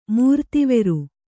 ♪ mūrtiveru